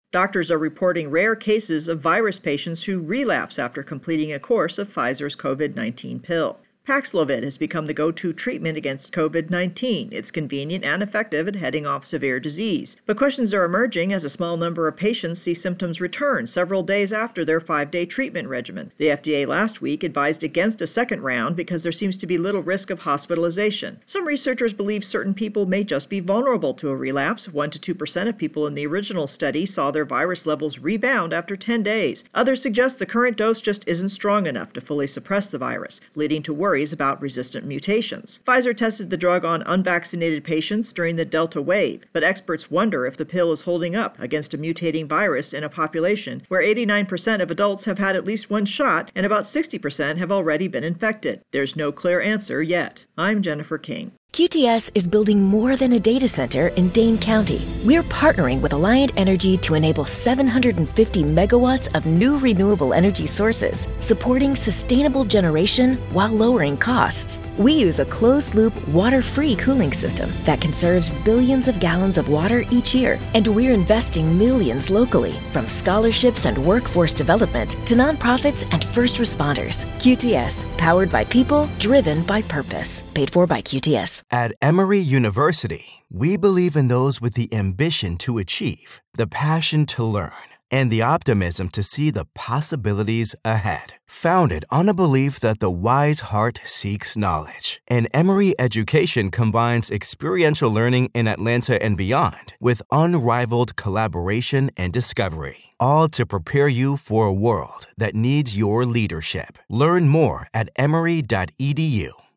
Virus Outbreak COVID Pill voicer with intro